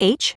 OCEFIAudio_en_LetterH.wav